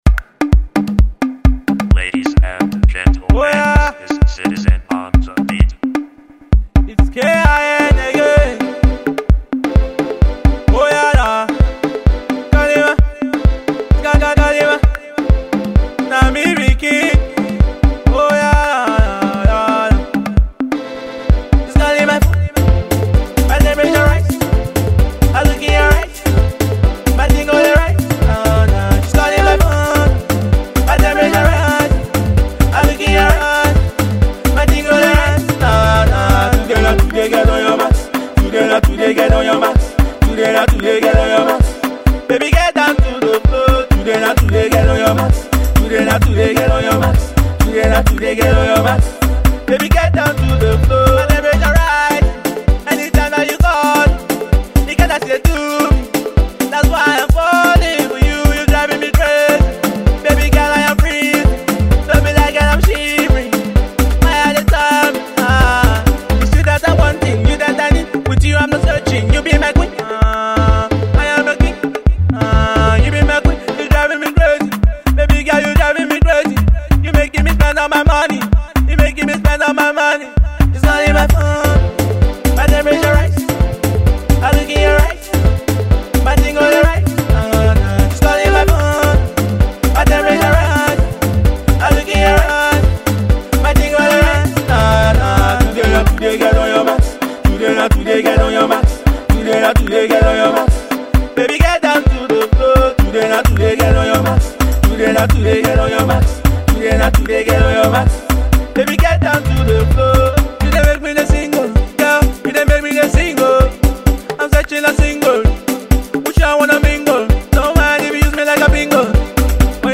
UK-based Afro-Pop artist
club jam